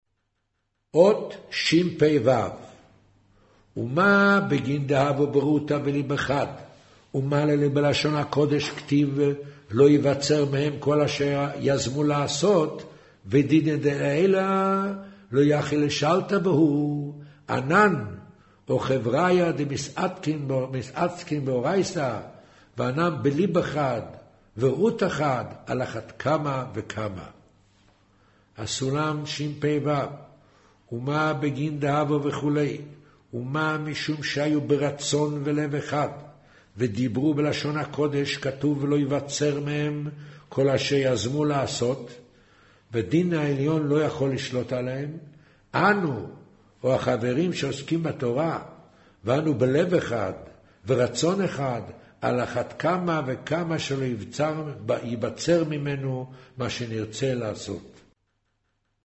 קריינות זהר